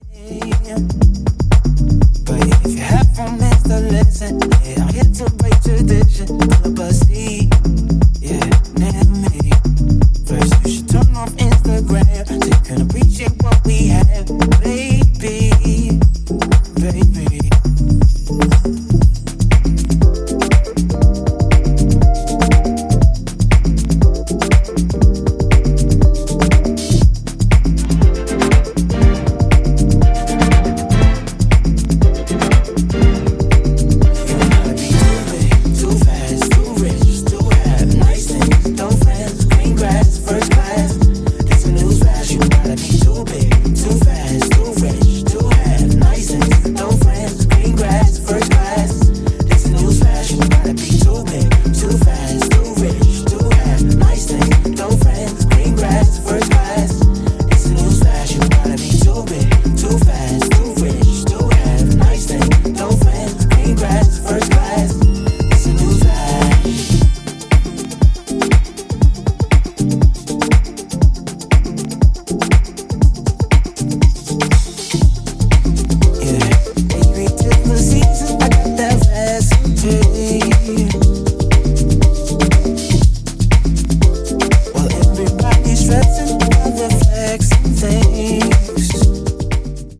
ジャンル(スタイル) SOULFUL HOUSE / DEEP HOUSE